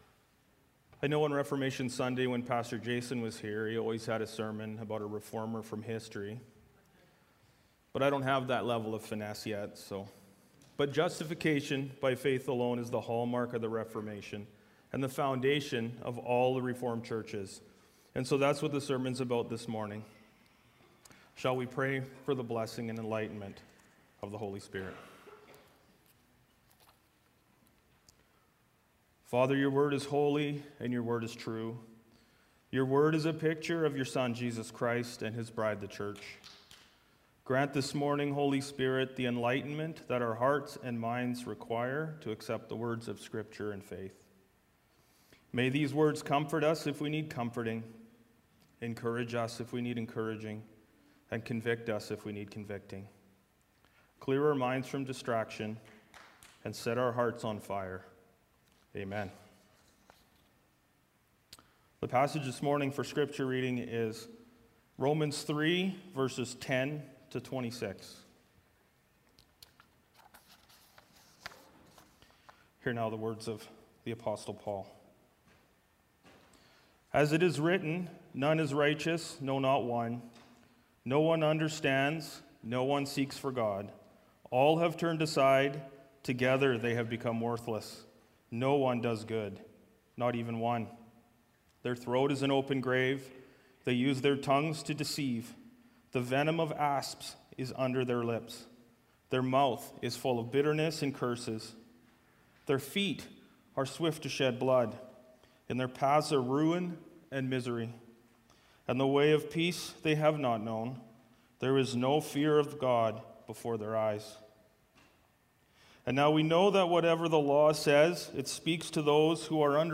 Sermons | Ebenezer Christian Reformed Church
Guest Speaker